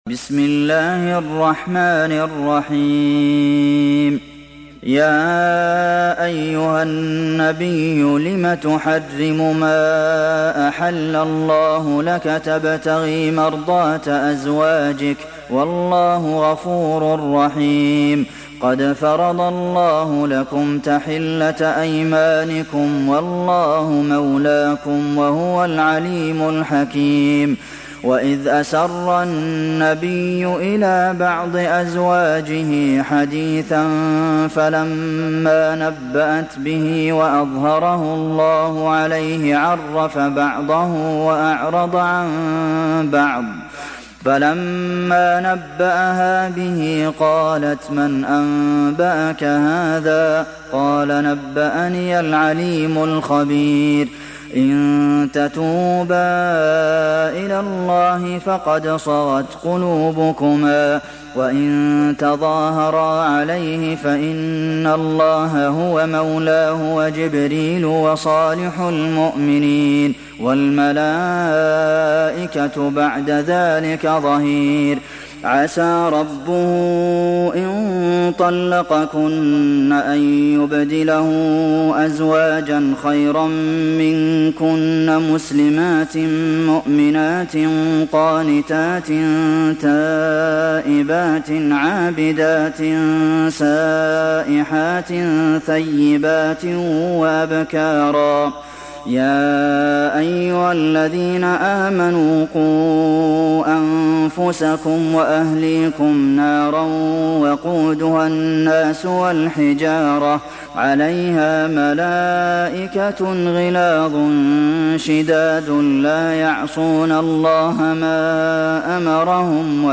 دانلود سوره التحريم mp3 عبد المحسن القاسم روایت حفص از عاصم, قرآن را دانلود کنید و گوش کن mp3 ، لینک مستقیم کامل